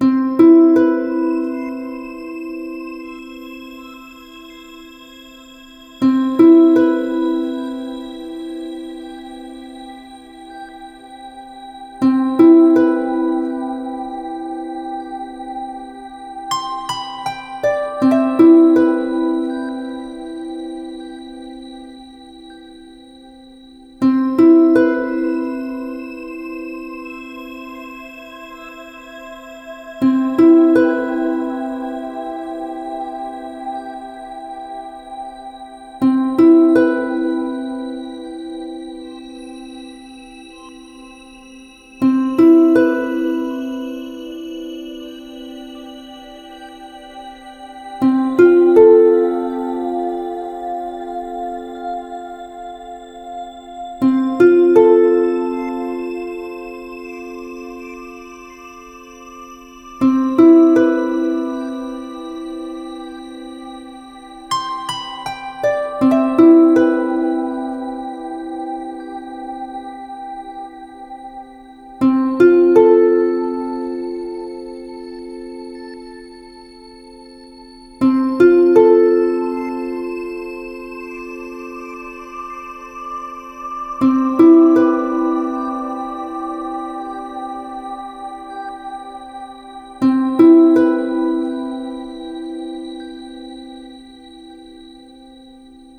ゆったりした楽曲
【イメージ】ヒーリング、スピリチュアル など